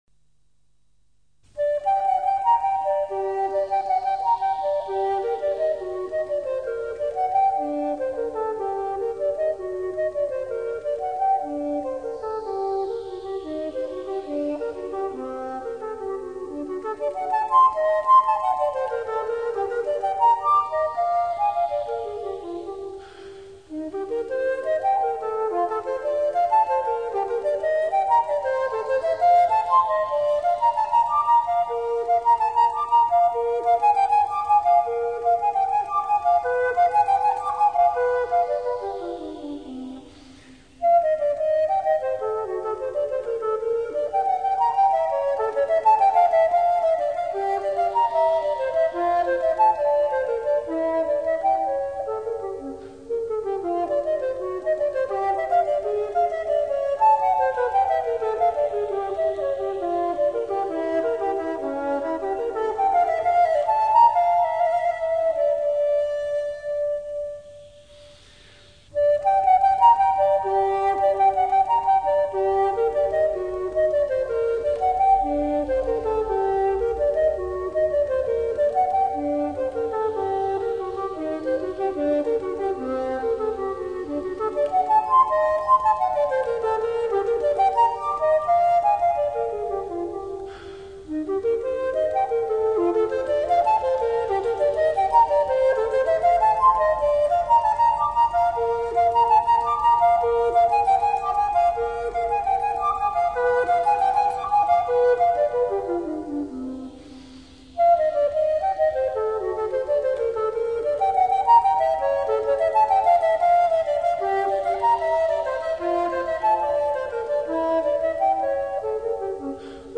Solo Flute Music from Six Centuries